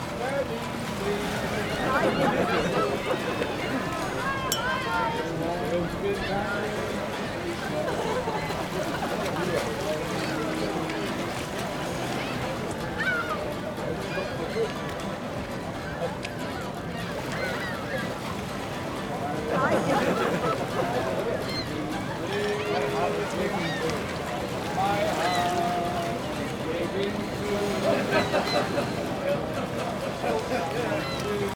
TNB_ASMR_ECV_30_Mix001.DfsZExeX.mp3